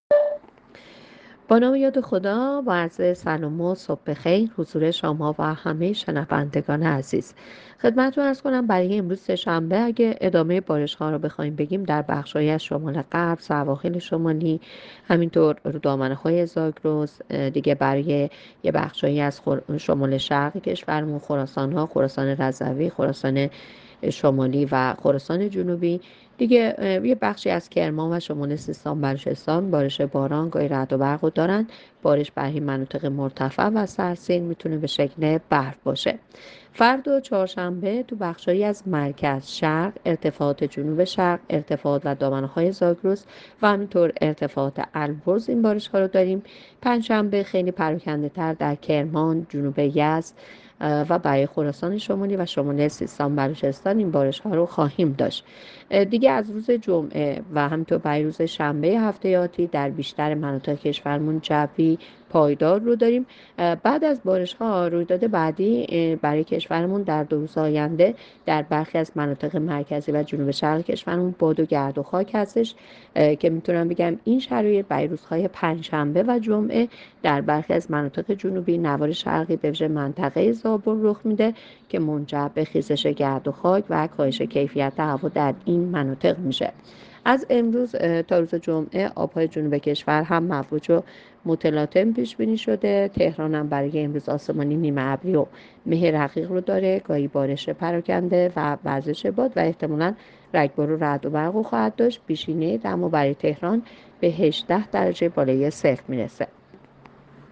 گزارش رادیو اینترنتی پایگاه‌ خبری از آخرین وضعیت آب‌وهوای ۲۱ اسفند؛